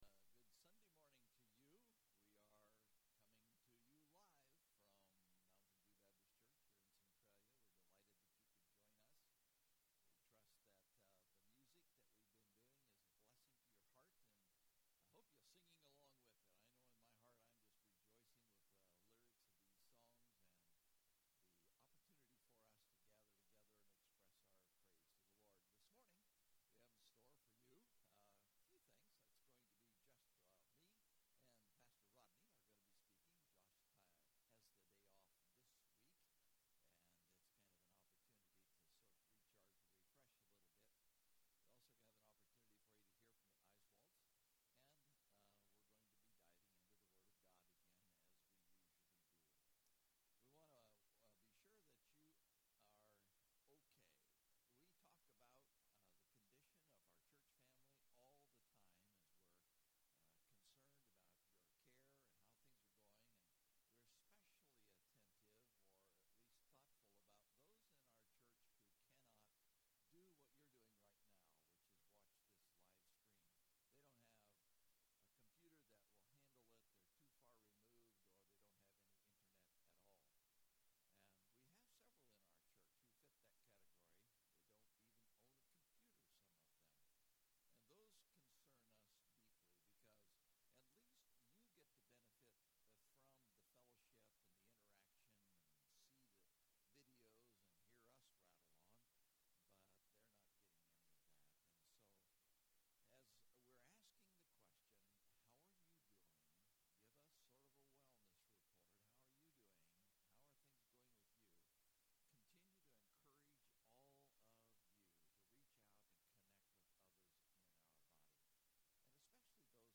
Online Church Service